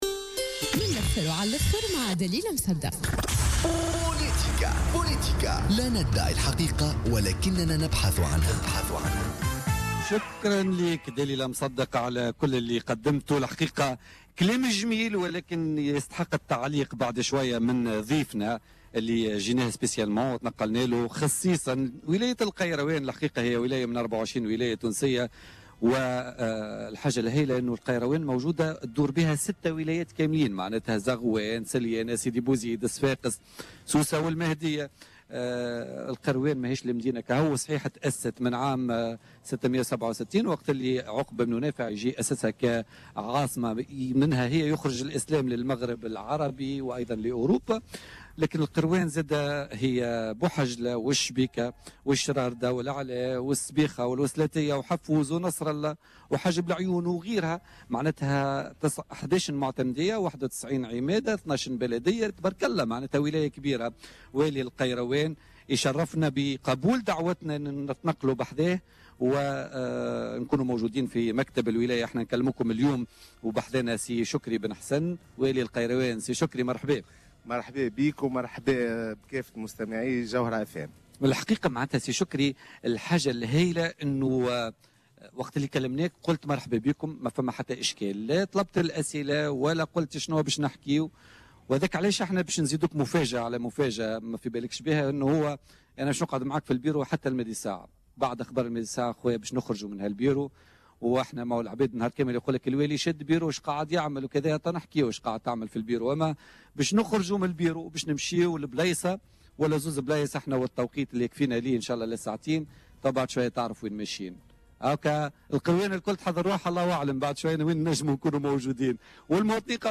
Le gouverneur de Kairouan, Chokri Ben Hassan a indiqué lors de son passage dans l’émission Politica du jeudi 18 février 2016, que le lancement des travaux de réaménagement de la route nationale numéro 12, nommée « la route de la mort » reliant Kairouan à Sousse, seront entamés durant le mois de mars prochain.